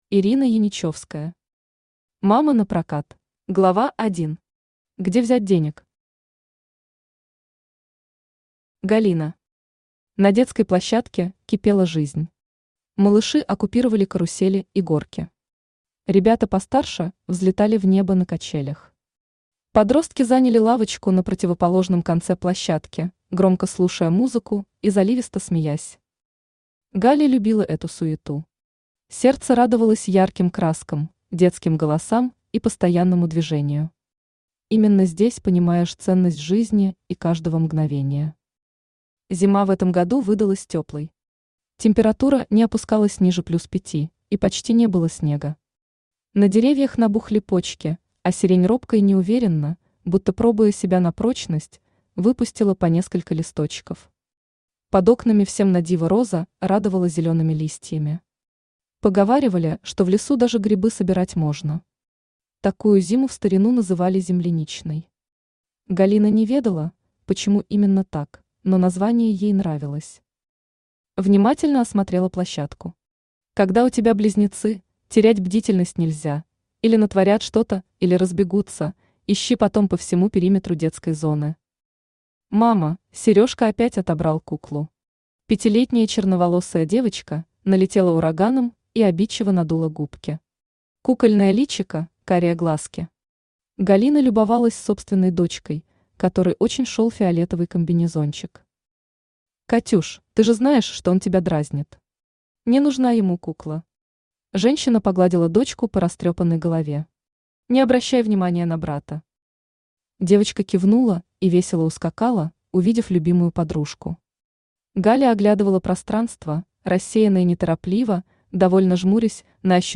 Аудиокнига Мама напрокат | Библиотека аудиокниг
Aудиокнига Мама напрокат Автор Ирина (Не Ваниль) Яничевская Читает аудиокнигу Авточтец ЛитРес.